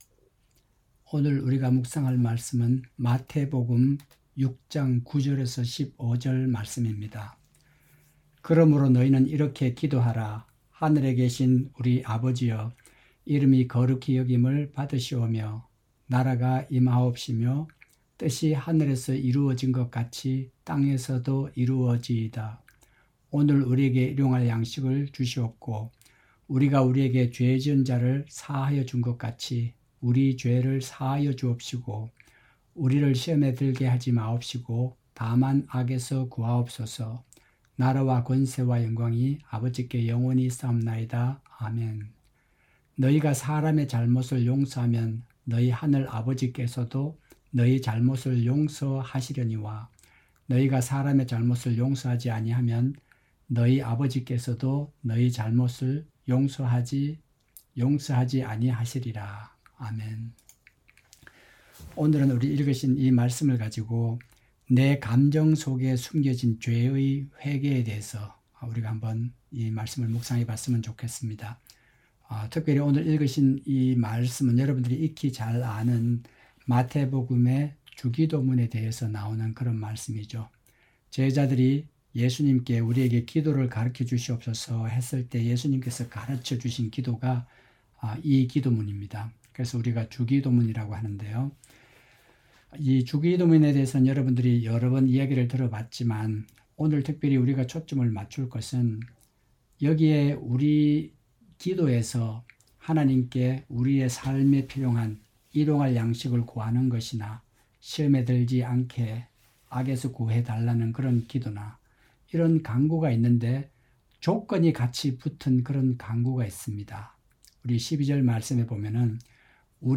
새벽설교